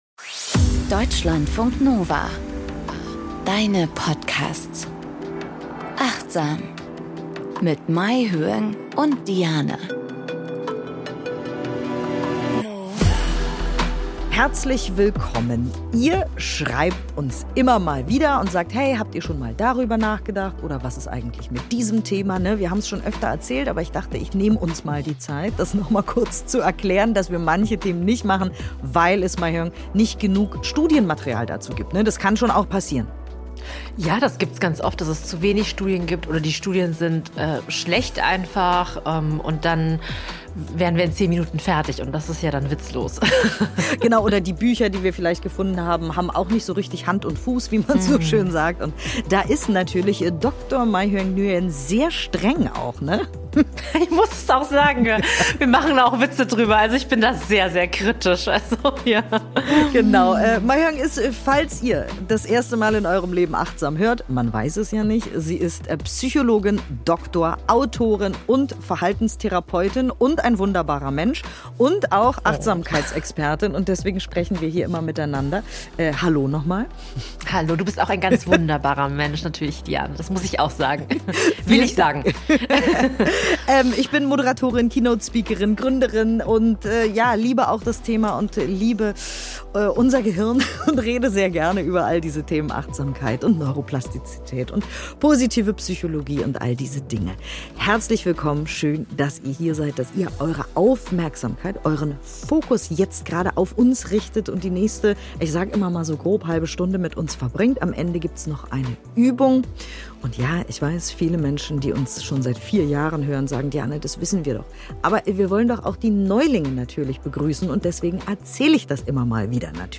Journalistisch und wissenschaftlich fundiert - inklusive Achtsamkeitsübung am Ende jeder Folge.